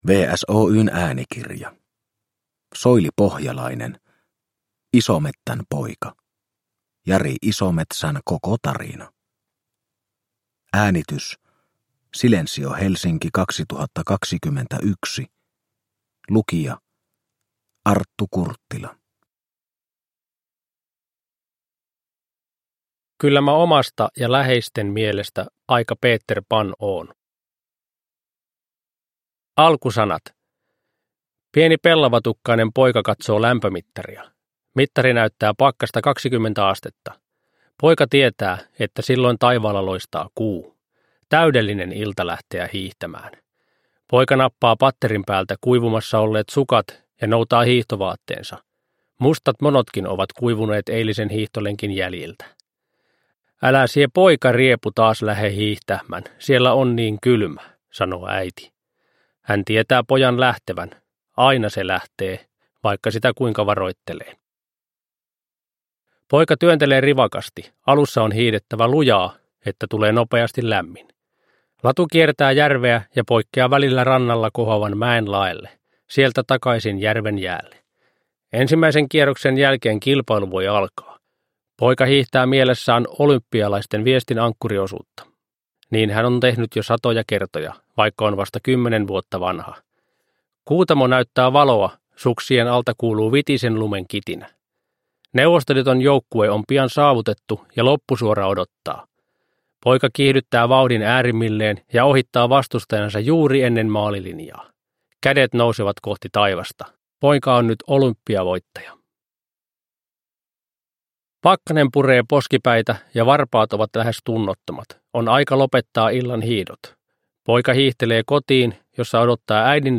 Isomettän poika - Jari Isometsän koko tarina – Ljudbok – Laddas ner